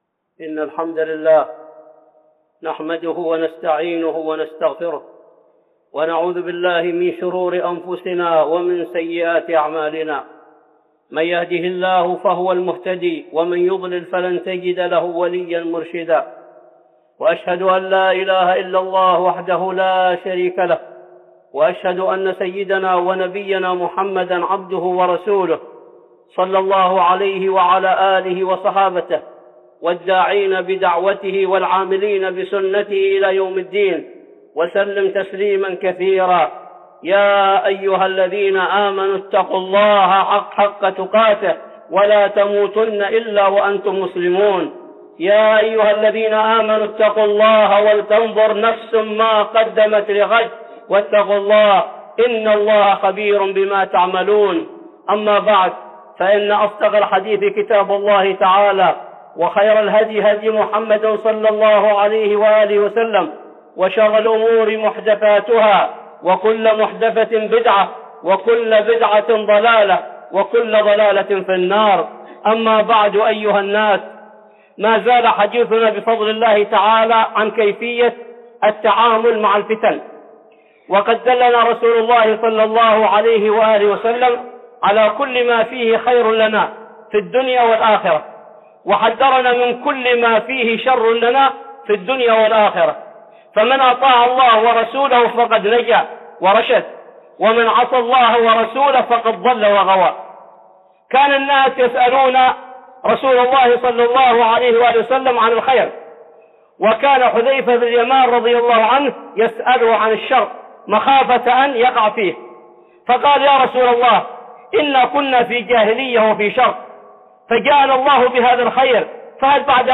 (خطبة جمعة) الفتن 2
خطبة جمعة بعنوان الفتن 2